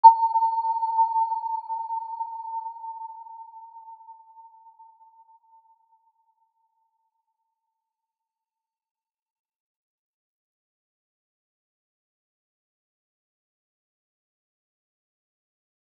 Aurora-C6-mf.wav